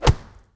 BODY HIT 1.WAV